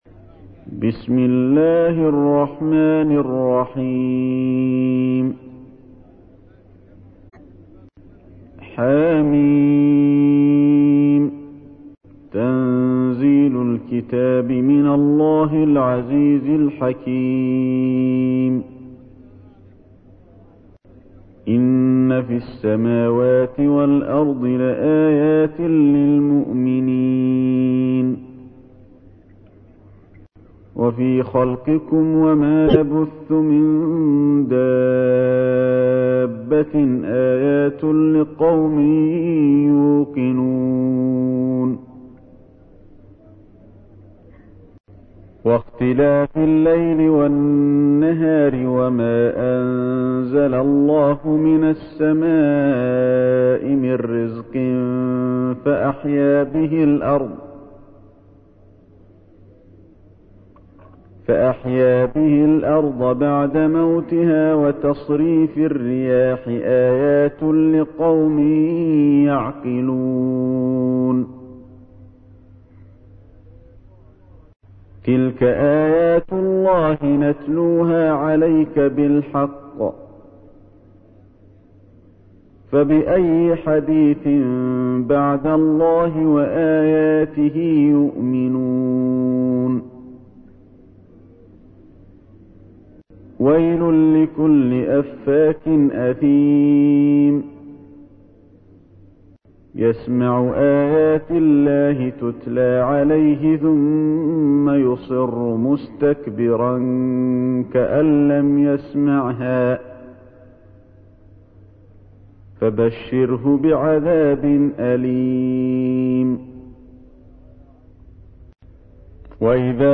تحميل : 45. سورة الجاثية / القارئ علي الحذيفي / القرآن الكريم / موقع يا حسين